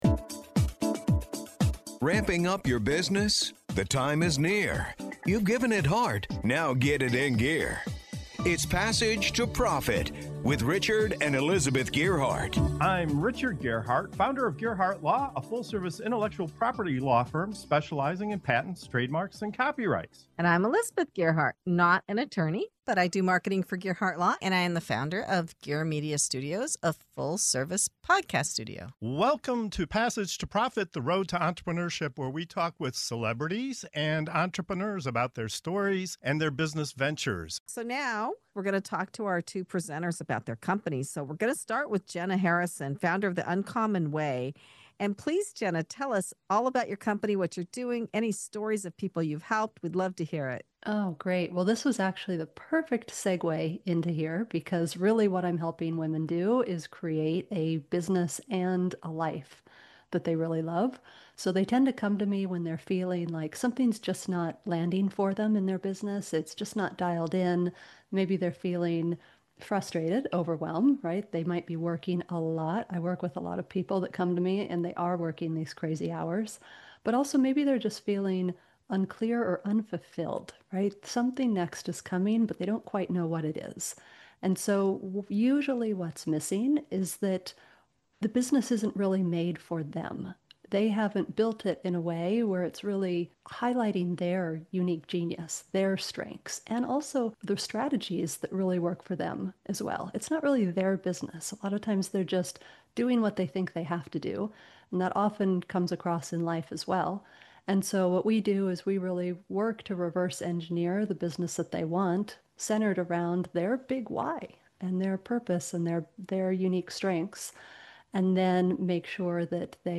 In this interview